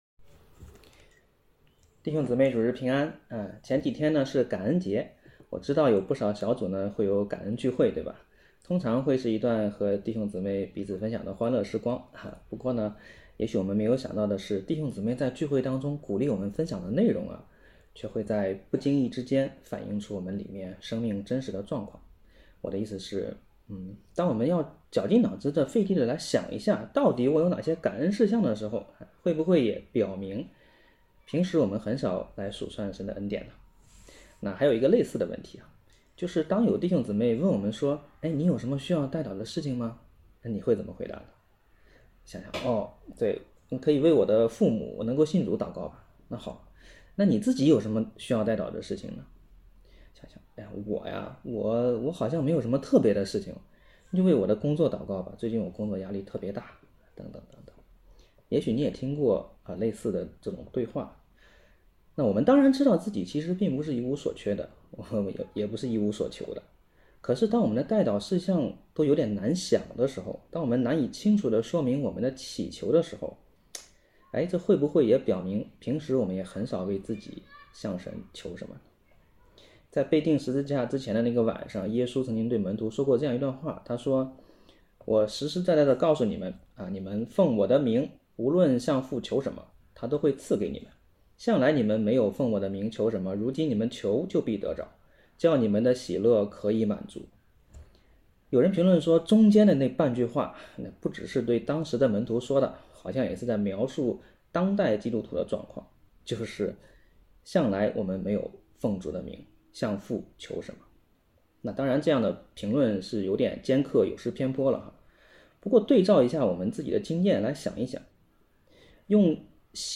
北京守望教会2024年12月1日主日敬拜程序